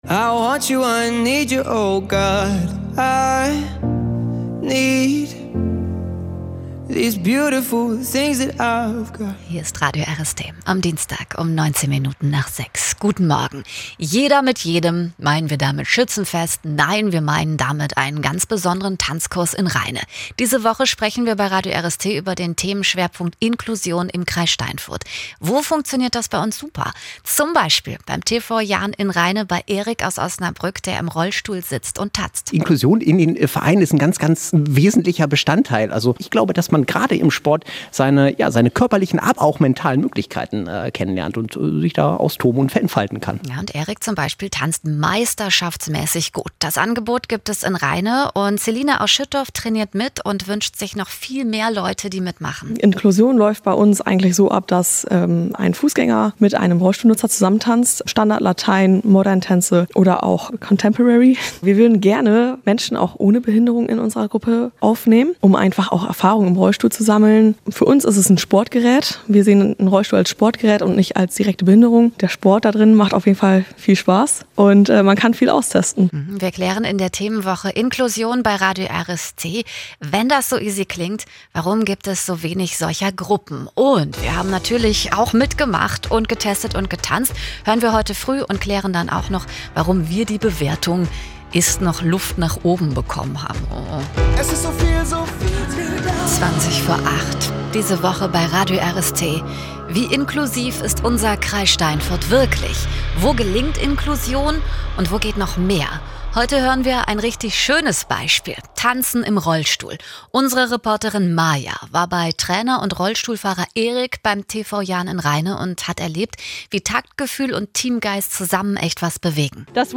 Radiobeitrag zum Thema Rollstuhltanzsport